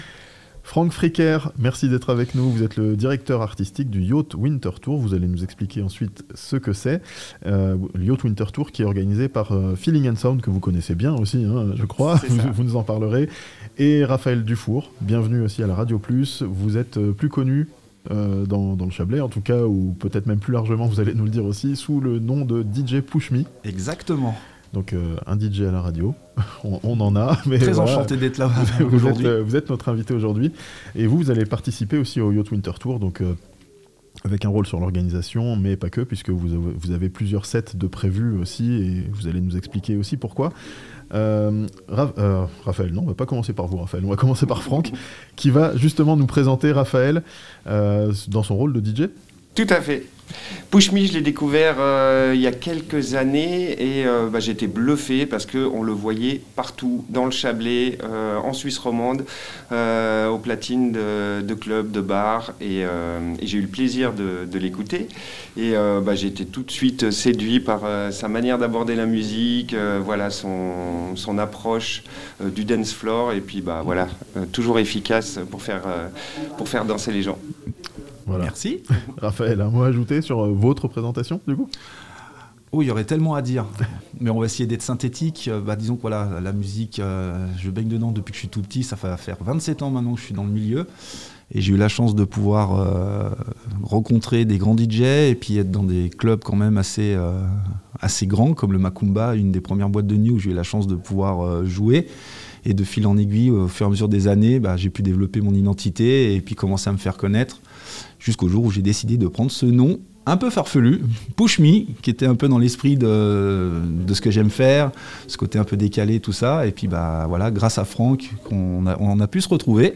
Avec le Yaute Winter Tour, la musique électronique s'invite dans les stations de ski (interview)